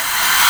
c_viper_hit2.wav